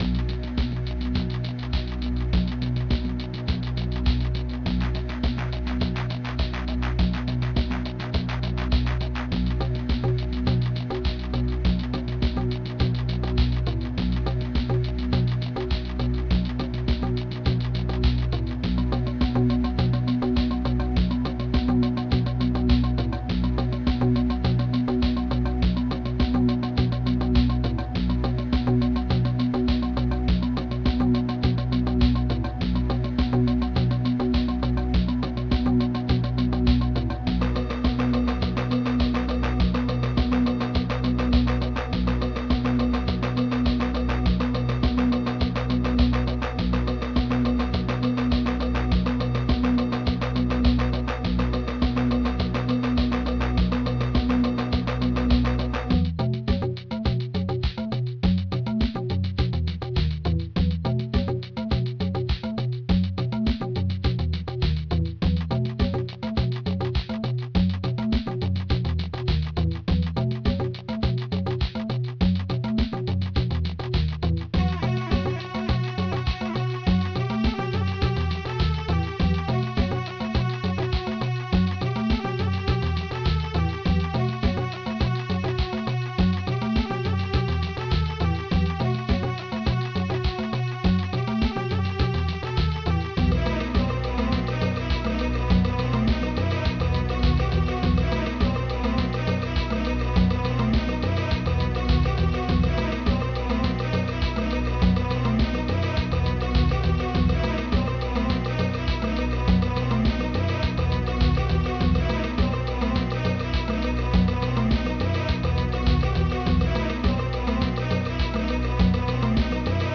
mod (ProTracker MOD (6CHN))
Fast Tracker 6CHN